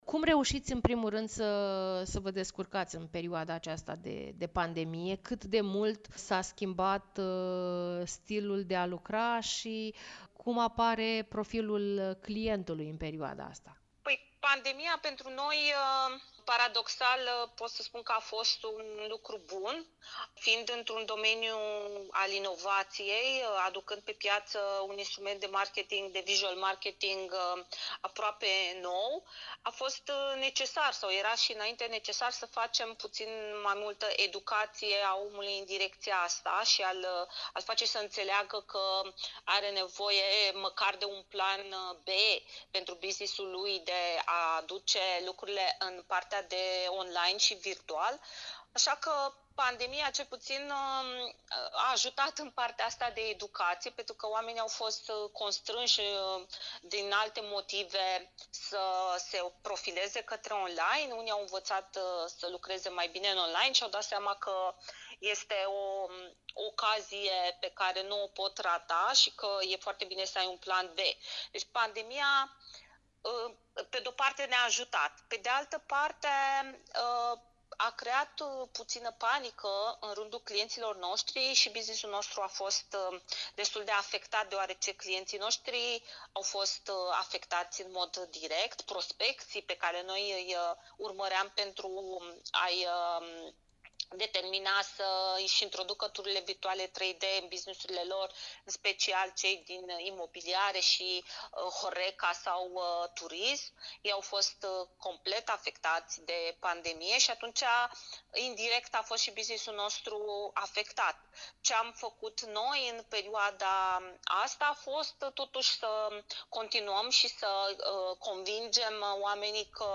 (INTERVIU) De ce tururi virtuale 3D pentru firme - Radio Iaşi – Cel mai ascultat radio regional - știri, muzică și evenimente